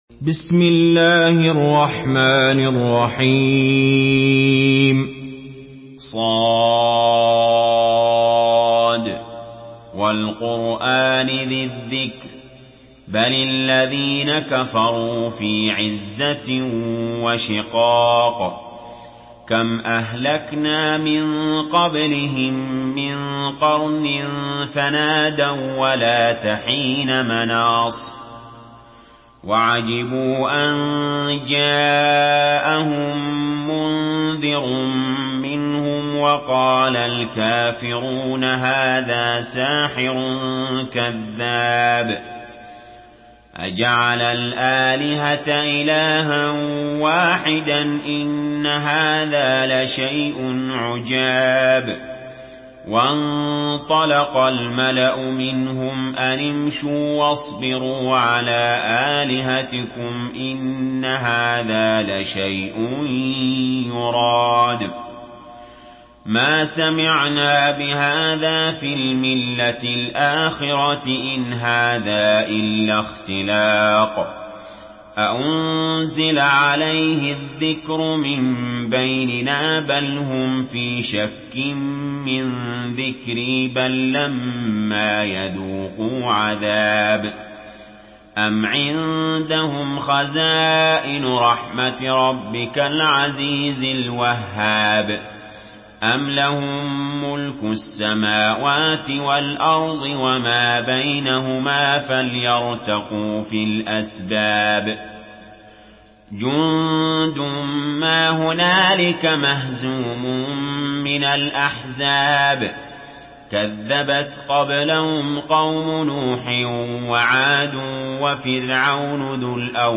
Surah Sad MP3 in the Voice of Ali Jaber in Hafs Narration
Murattal